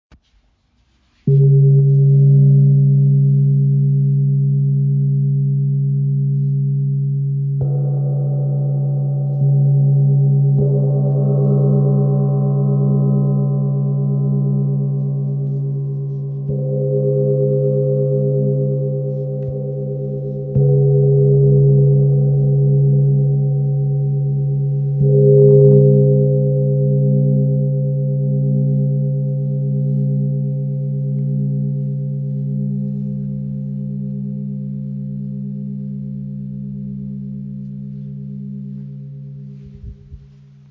Tam Tam Gong | Chao Gong | Ø 50 cm im Raven-Spirit WebShop • Raven Spirit
Klangbeispiel
Der Sound ist voluminös und erinnert im Aufbau an übereinander liegende Klangebenen. So kann ein Soundgebilde aus Bass und Obertönen entstehen. Solche Gongs mit Rand erzeugen einen sehr meditativen Gesamtklang.